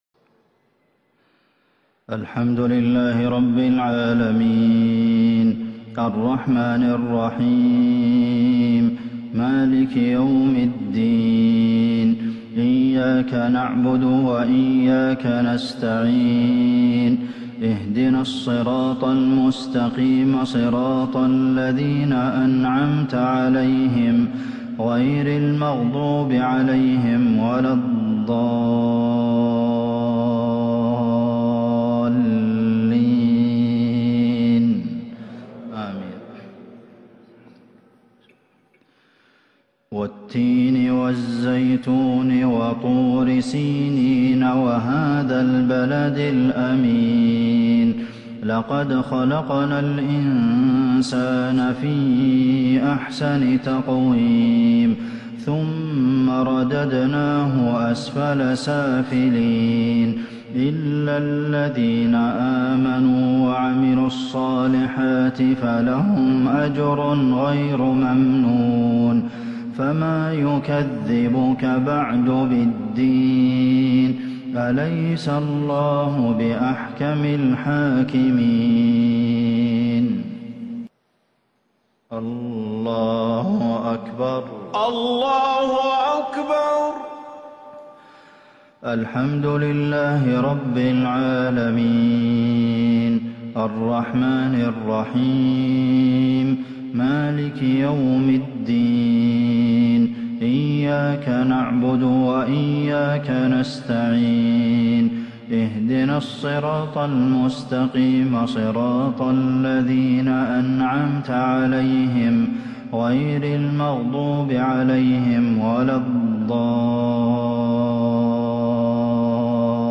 مغرب الأربعاء 1-5-1442هـ سورتي التين والماعون | Maghrib prayer Surah At-Tin and alMa'un 16/12/2020 > 1442 🕌 > الفروض - تلاوات الحرمين